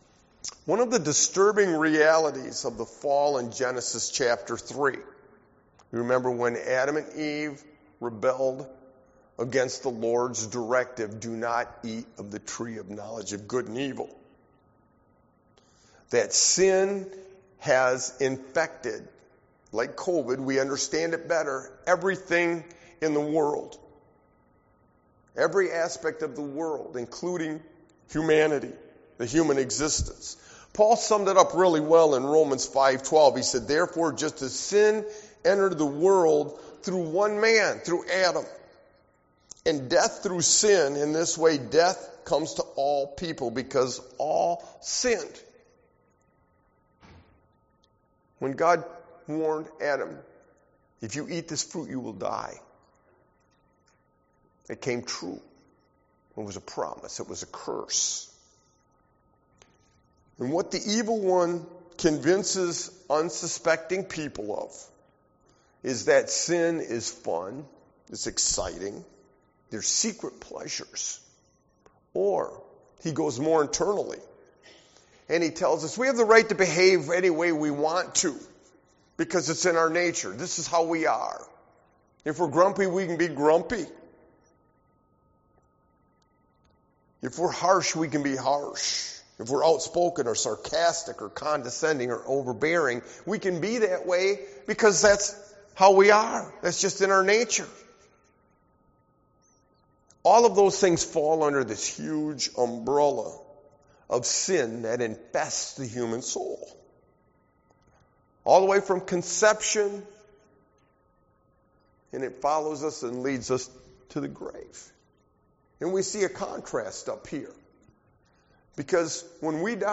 Sermon-Why-a-Sovereign-God-is-crucial-XII-5122.mp3